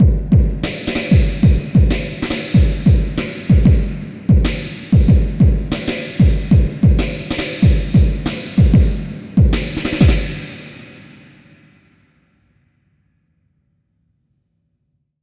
Track 13 - Drum Break 02.wav